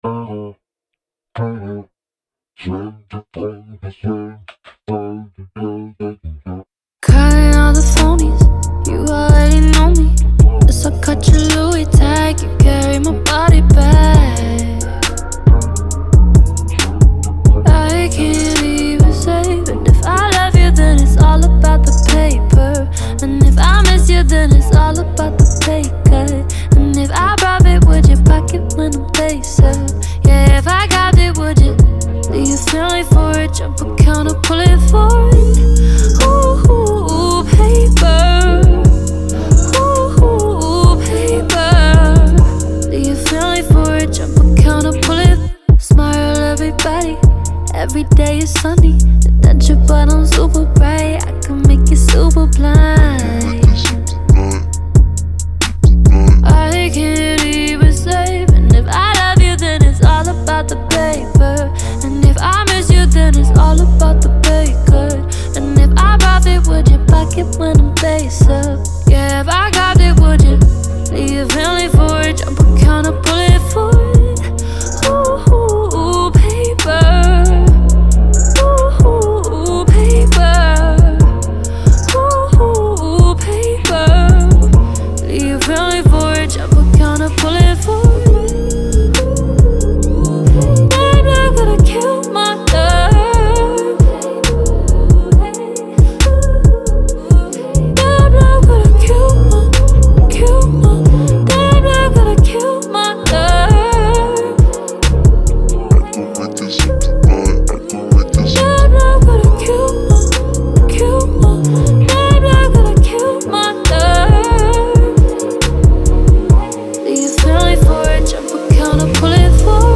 BPM136-136
Audio QualityPerfect (High Quality)
Trap song for StepMania, ITGmania, Project Outfox
Full Length Song (not arcade length cut)